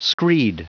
Prononciation du mot screed en anglais (fichier audio)
Prononciation du mot : screed